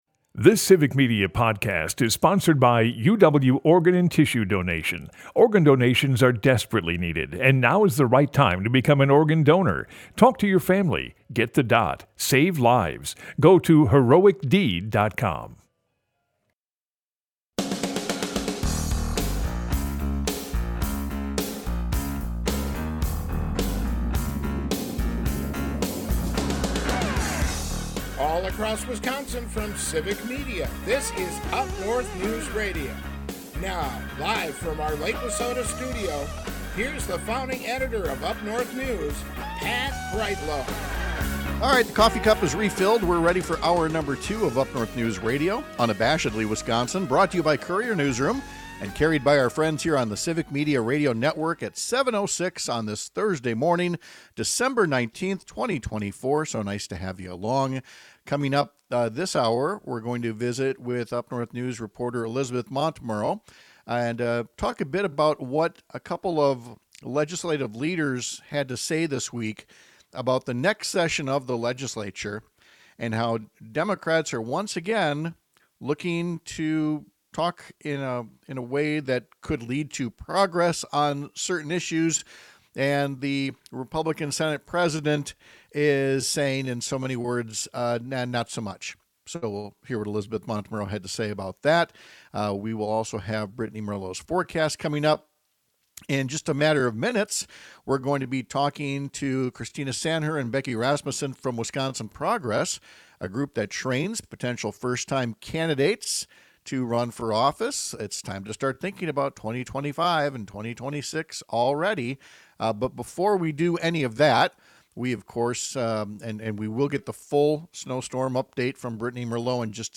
Civic Media: Hometown radio refreshed